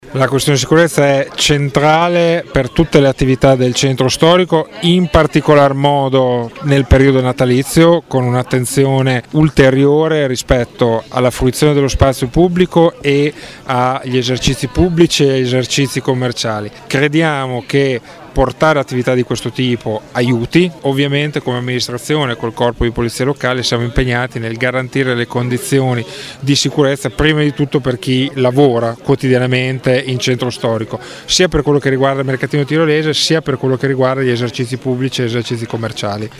Sentiamo, proprio sulla sicurezza, l’assessore Andrea Bortolamasi, ieri, all’inaugurazione dei mercatini: